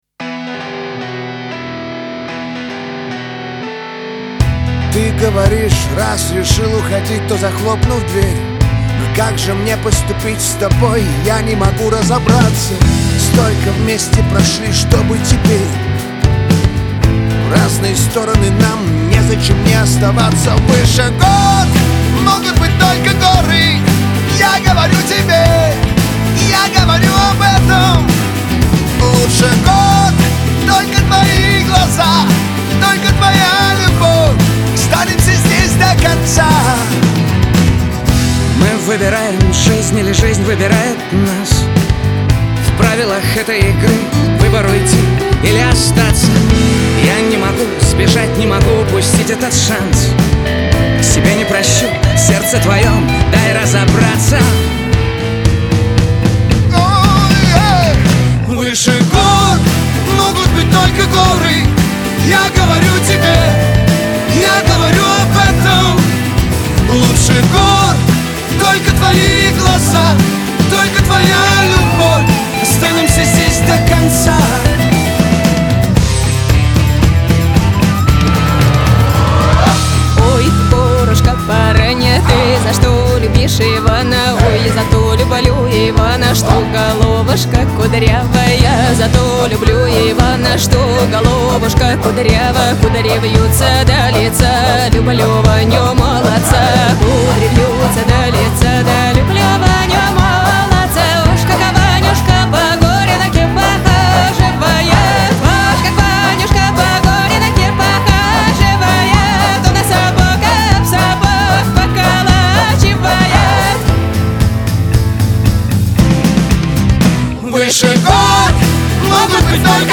pop
дуэт